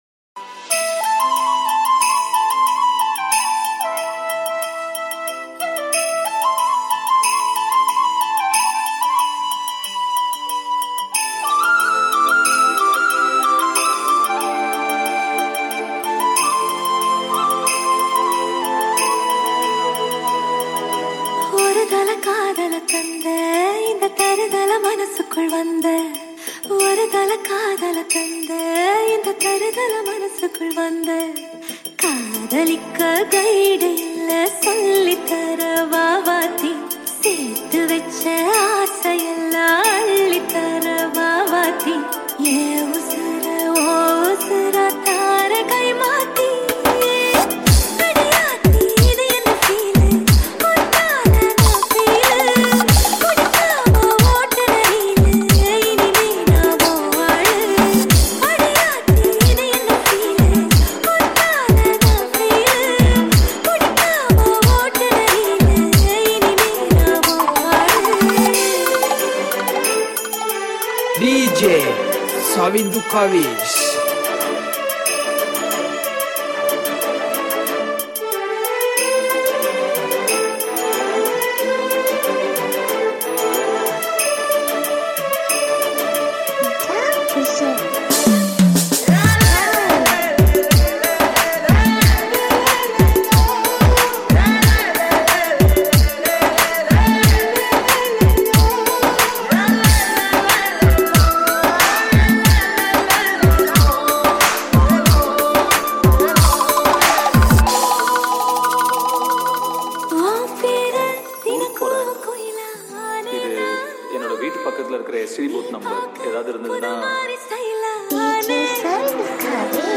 High quality Sri Lankan remix MP3 (14.6).